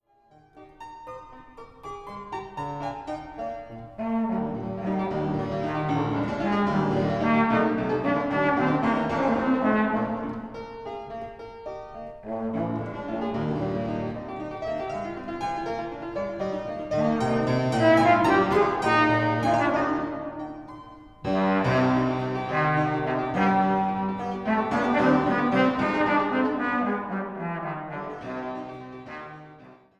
Klassische Posaune
Hammerklavier